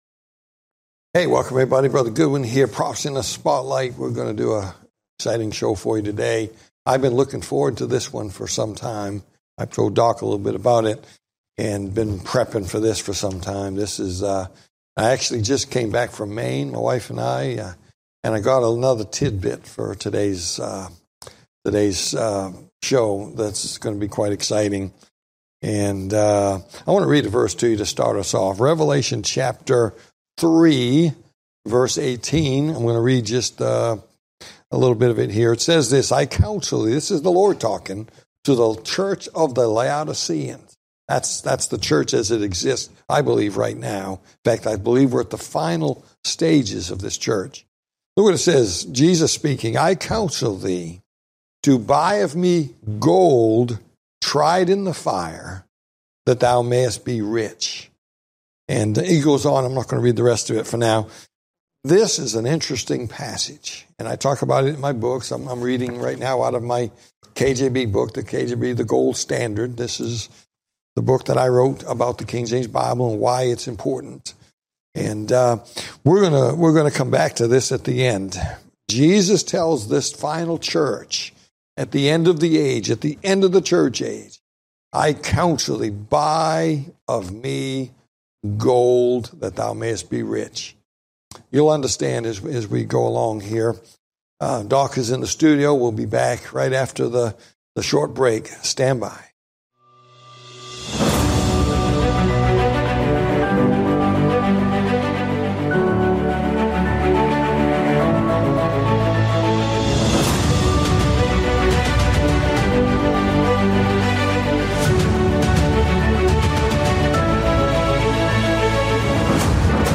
Talk Show Episode, Audio Podcast, Prophecy In The Spotlight and What Happened In 1900, and Storm Clouds Of Prophecy on , show guests , about What Happened In 1900,Storm Clouds Of Prophecy, categorized as History,News,Politics & Government,Religion,Society and Culture,Theory & Conspiracy